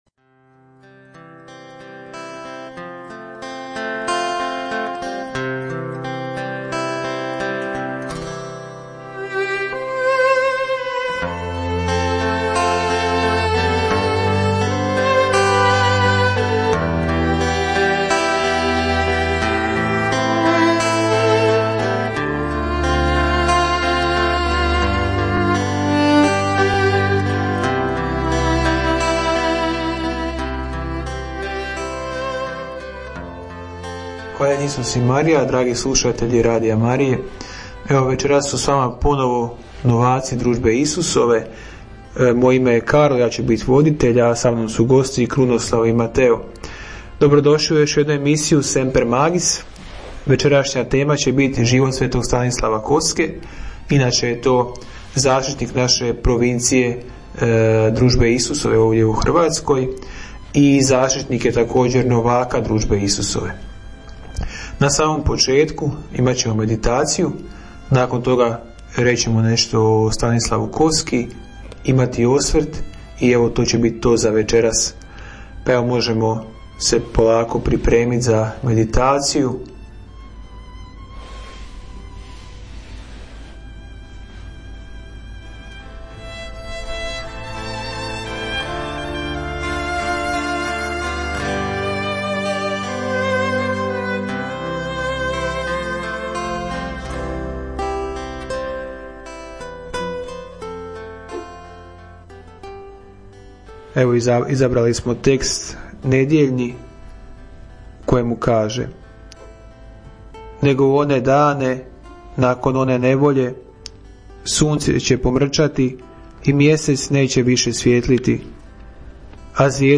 Radio Marija Hrvatska - Semper magis - emisija novaka Družbe Isusove; tema: Život sv. Stanislava Kostke